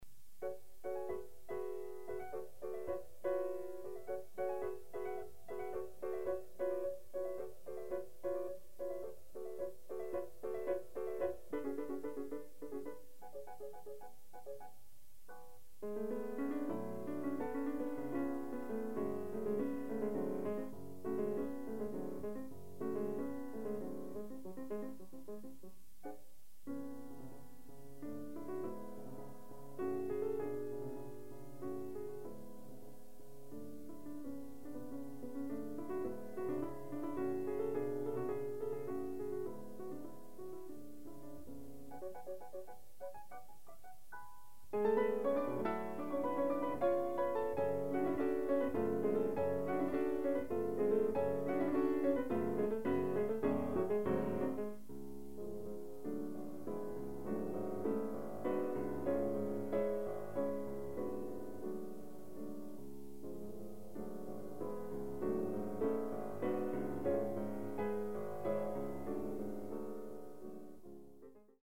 Soloist
Houstoun, Michael, 1952-
Recorded September 22, 1973 in the Ed Landreth Hall, Texas Christian University, Fort Worth, Texas
Suites (Piano)
Etudes
Sonatas (Piano)
performed music